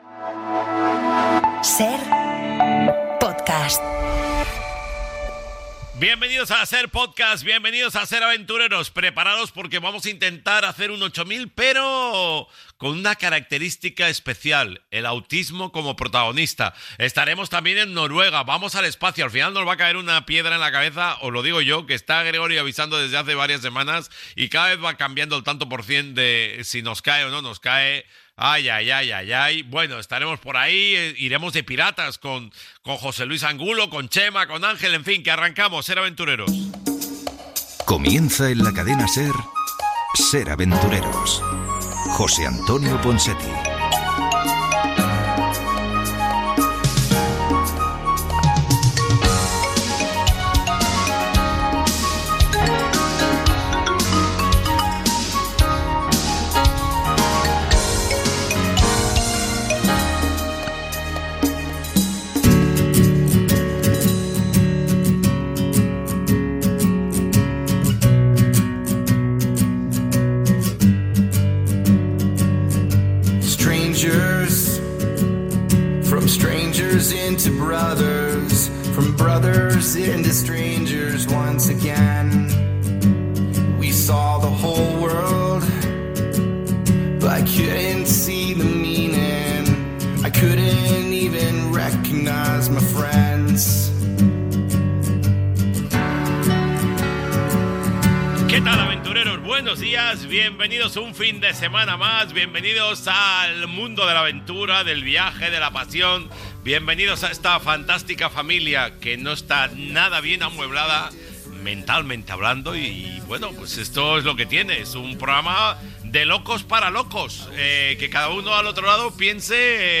Indicatiu de SER Podcast, presentació, indicatiu del programa, sumari del programa, esment als 30 anys del programa, salutació als col·laboradors i primeres intervencions seves
Entreteniment